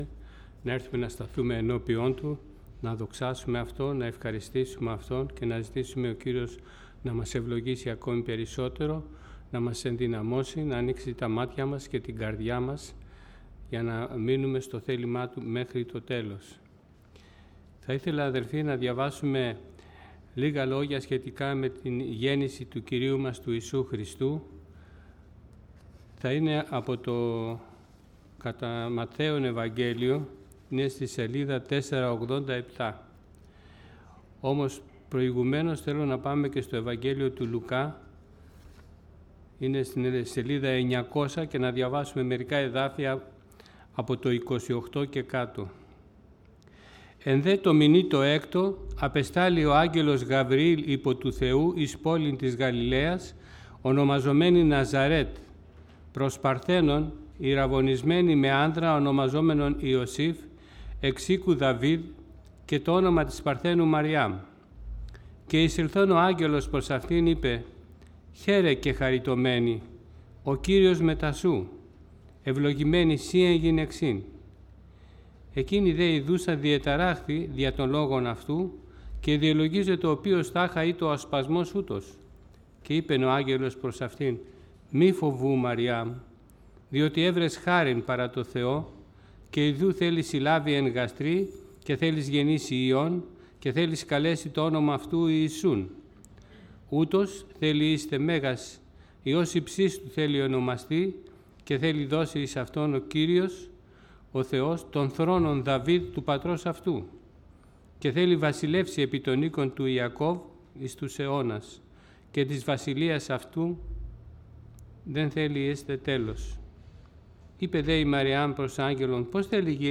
Series: Κήρυγμα Ευαγγελίου